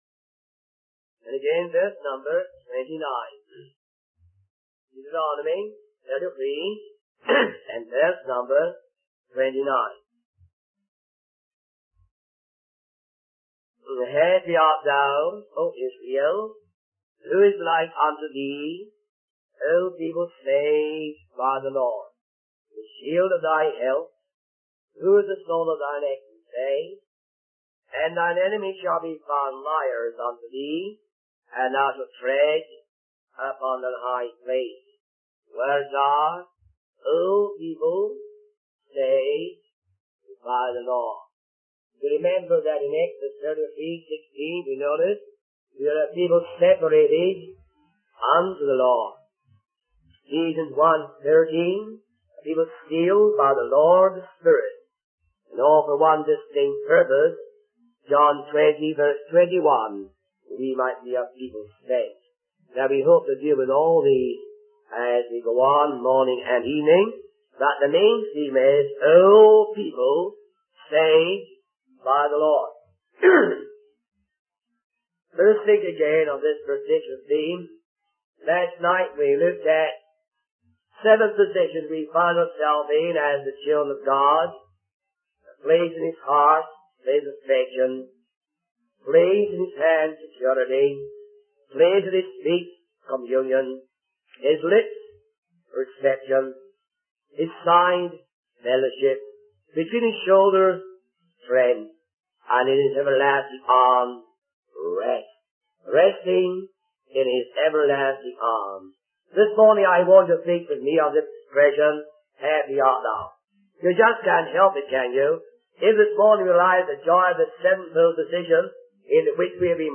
In this sermon, the preacher discusses the theme of being steeled by the law of the Spirit.